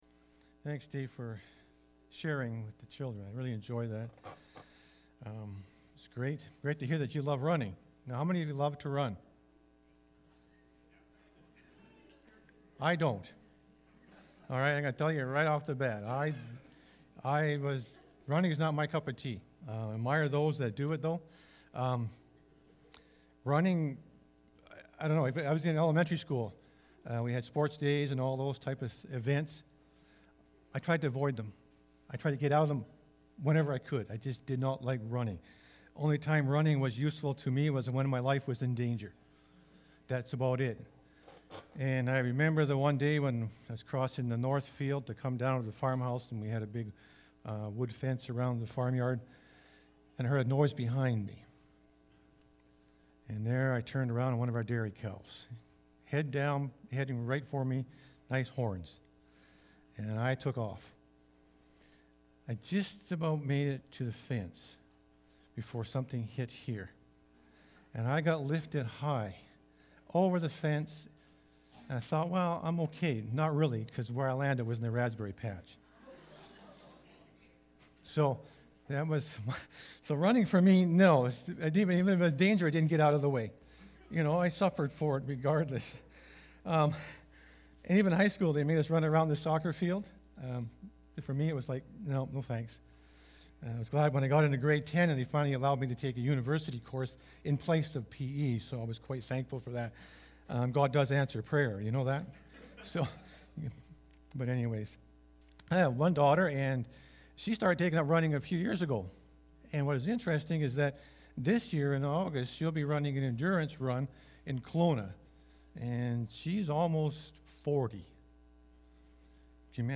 May-22-sermon-audio.mp3